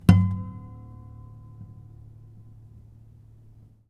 strings_harmonics
harmonic-05.wav